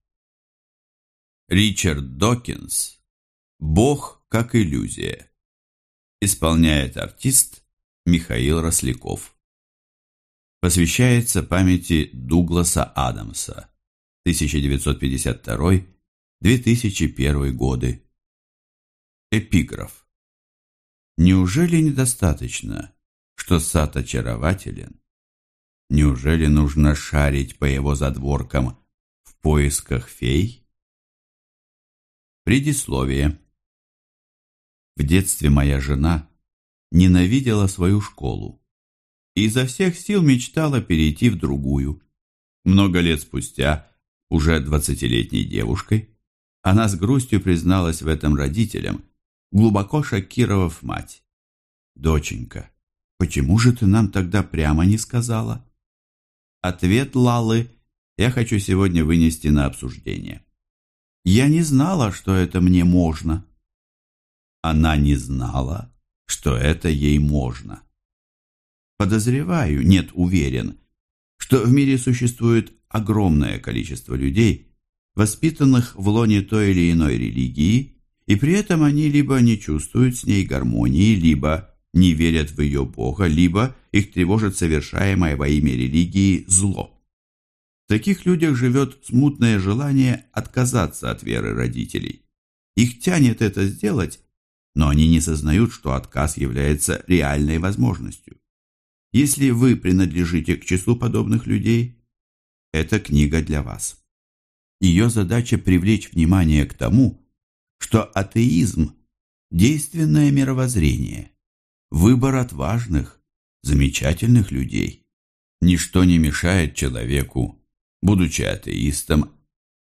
Аудиокнига Бог как иллюзия - купить, скачать и слушать онлайн | КнигоПоиск